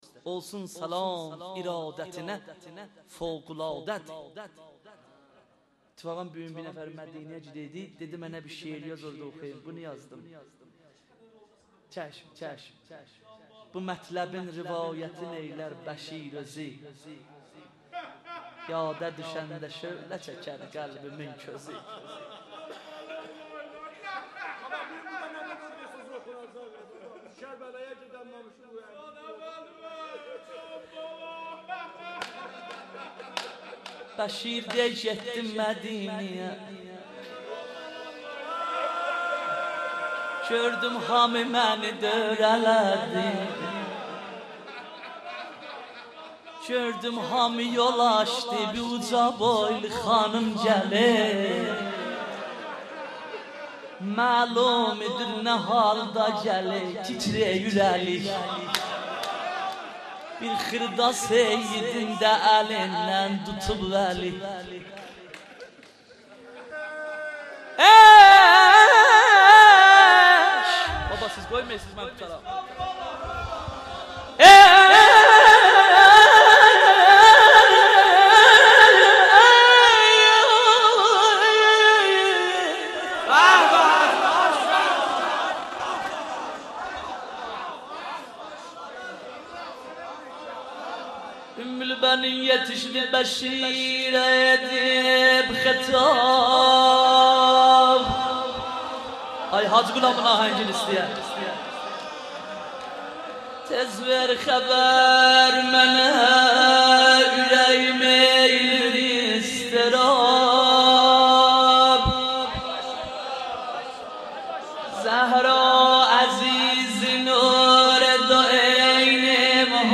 روضه آذری
در هیئت مکتب العباس (ع) زنجان